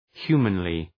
Προφορά
{‘hju:mənlı}